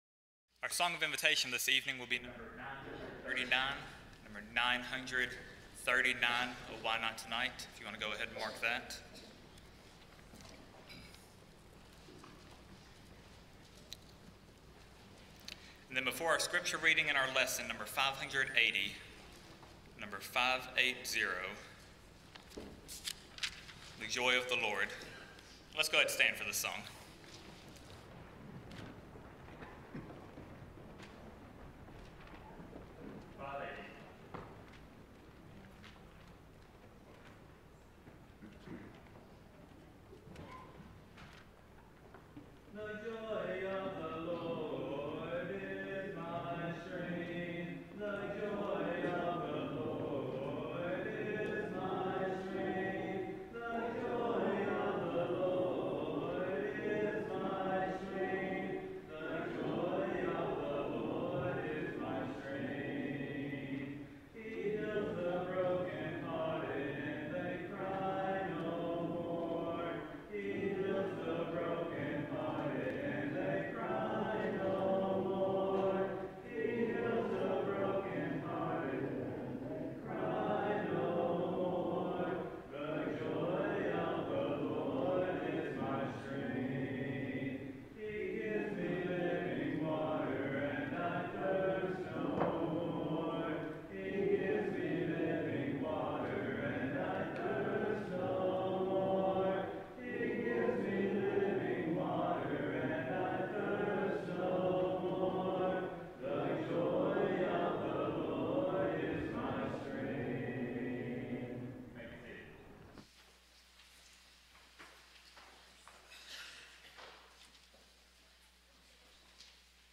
Psalms 46:10, English Standard Version Series: Sunday PM Service